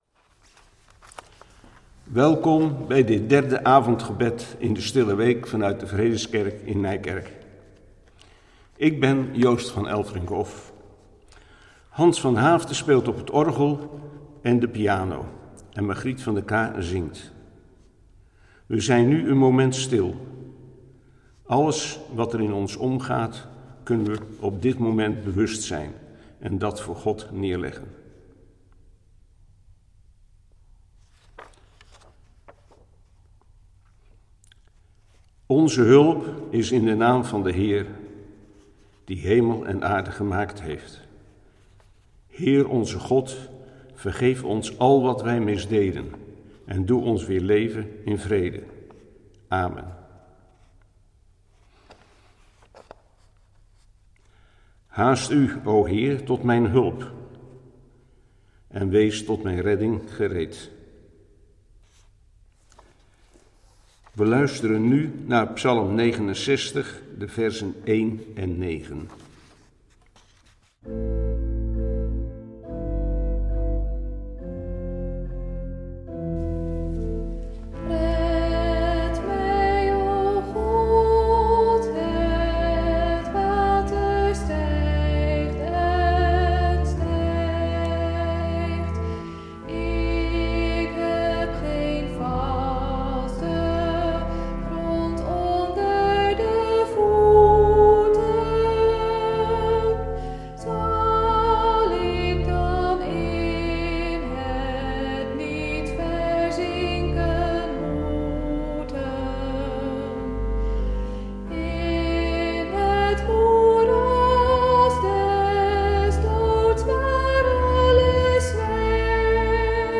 Zij zullen van tevoren opgenomen worden.
orgel en piano
sopraan
Dit is een opname uit een eerdere kerkdienst in de Vredeskerk.